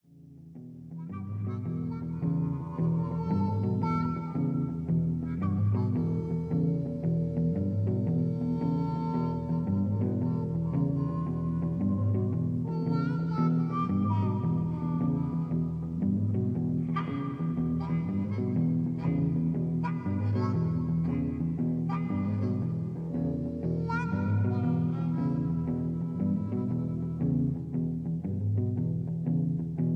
Tags: karaoke , backingtracks , soundtracks , rock